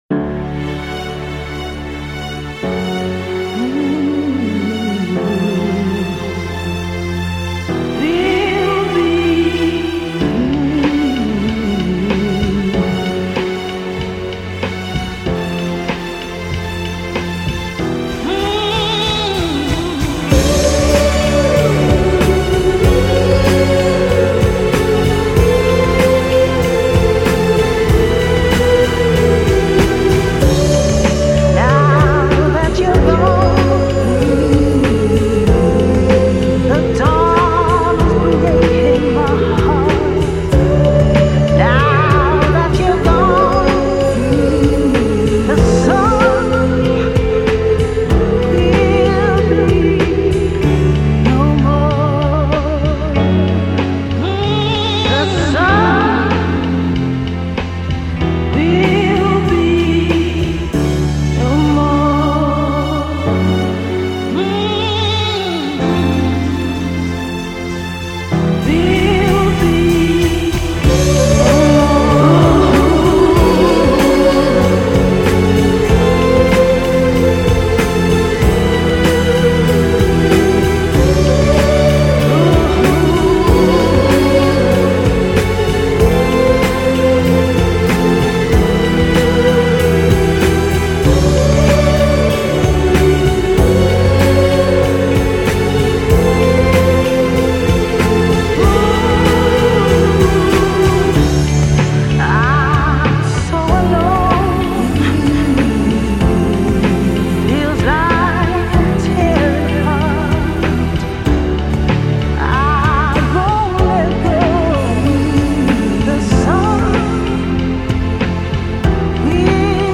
Wide-screen, Hi-Def Ethereal tonight.
You have to admit, it’s quite catchy.